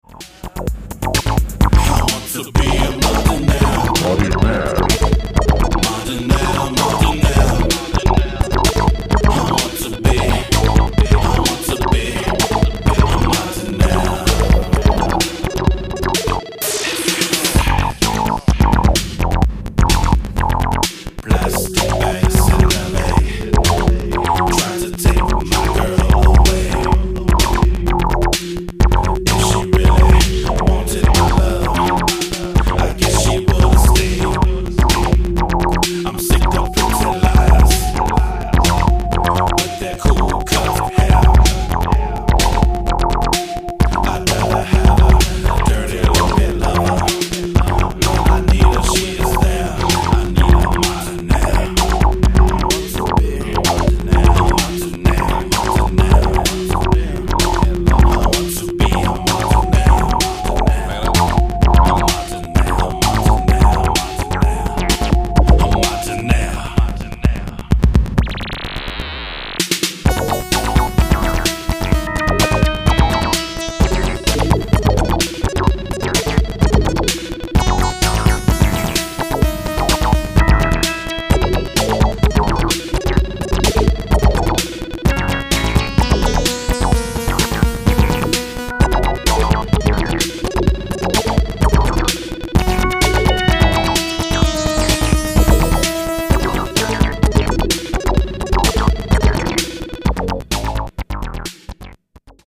Chart by sales chart: 2008 sales chart disco part 2